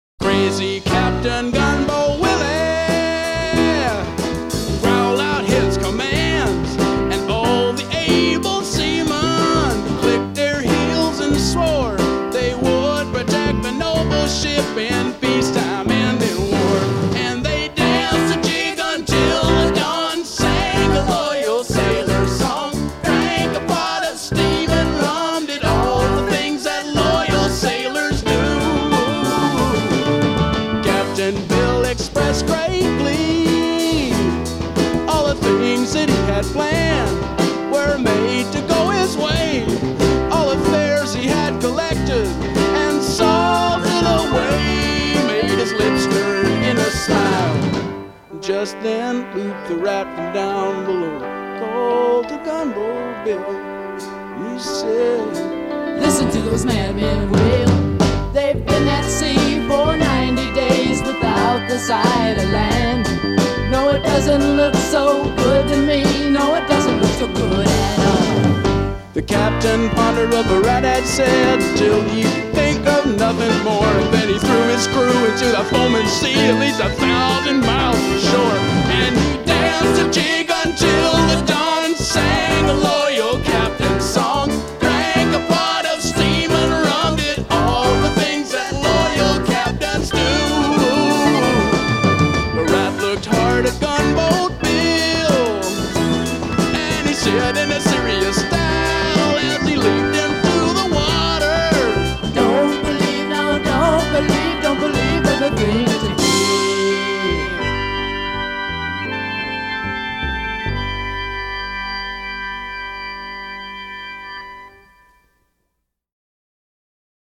Allmusic.comAMG）五星滿點推薦，藍味十足的南方搖滾經典之作！